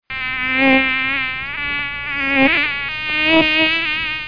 Le moustique | Université populaire de la biosphère
il bruisse et vrombit
moustique.mp3